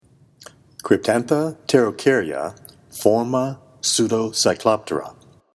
Pronunciation/Pronunciación:
Cryp-tán-tha  pte-ro-cár-ya forma pseudocycloptera